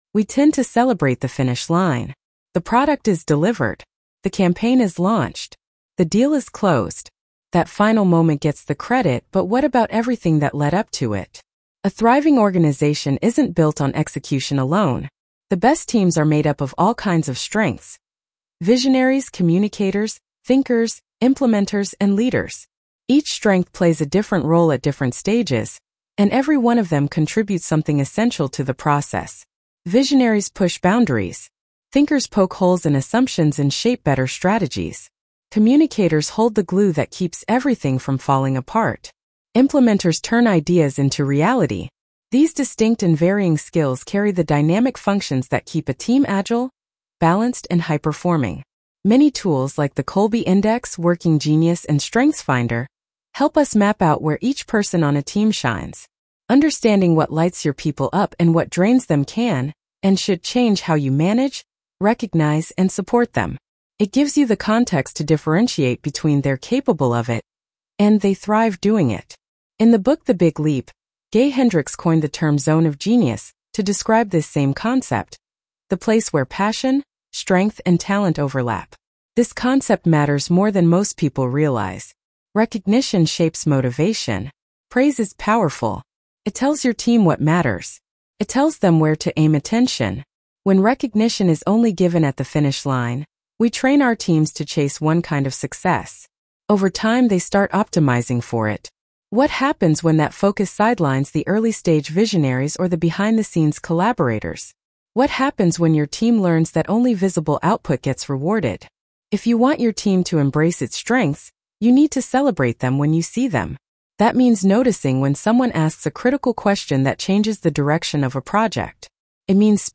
The Best Leaders Pay Attention to the Process, Not Just the Product Blog Narration.mp3